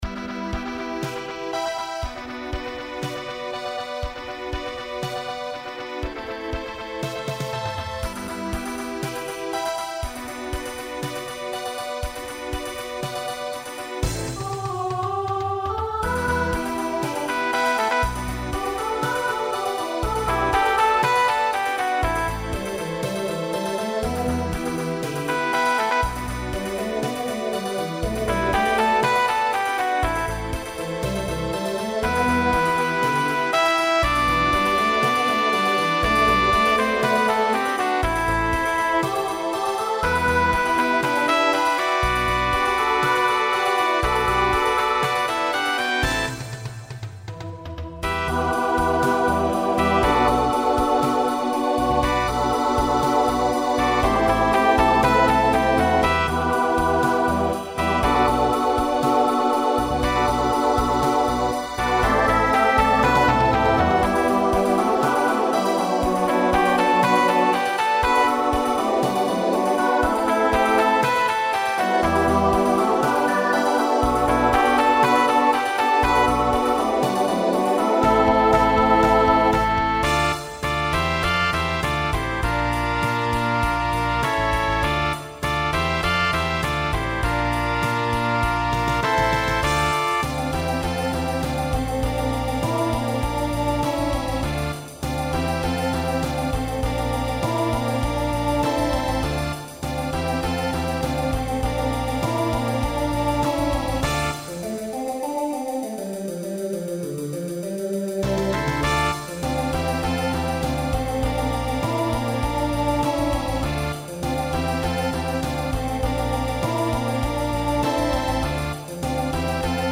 Voicing SATB Instrumental combo Genre Pop/Dance
Mid-tempo